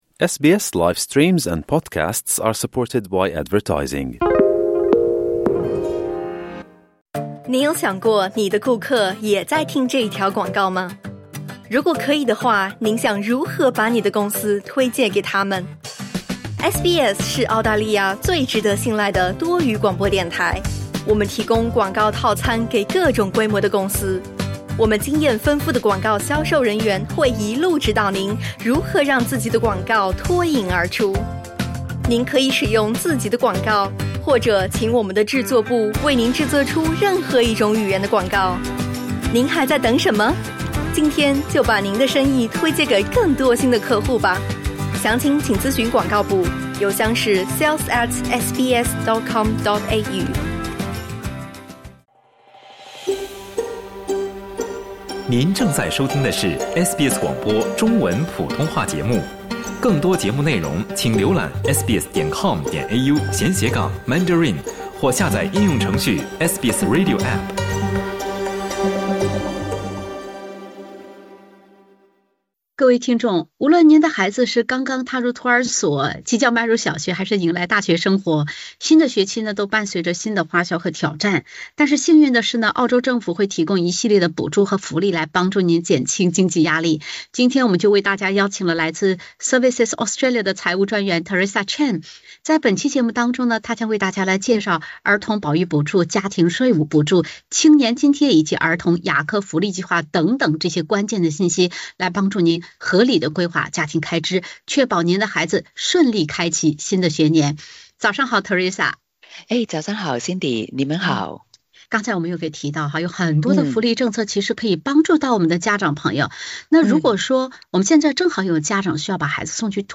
Services Australia的财务专员在新学年开学之际，为大家介绍各类政府补助，确保孩子顺利开启新学年。